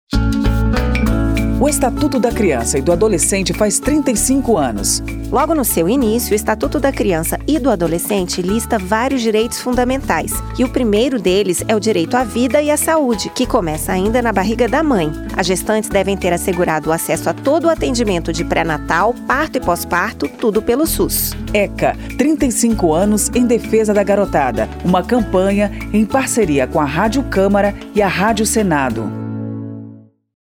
07-spot-eca-35-anos-parceiras.mp3